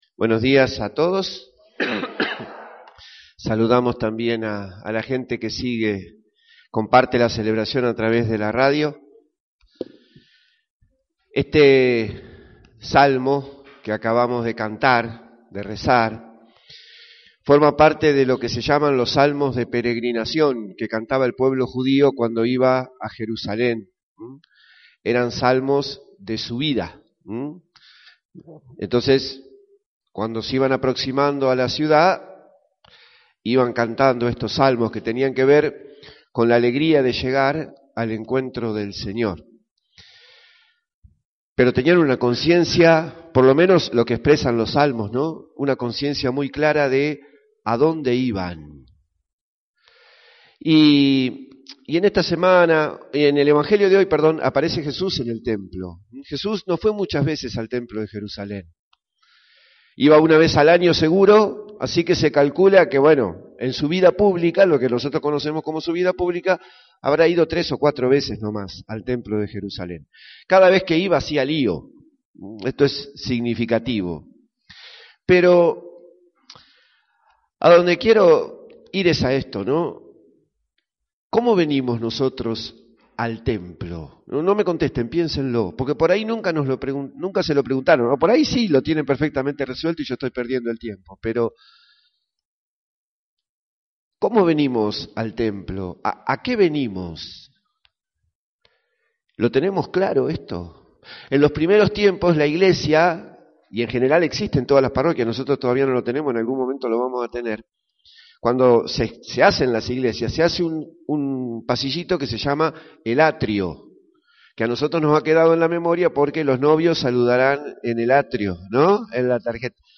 INTRODUCCIÓN A LA MISA